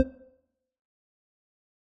jump_down.wav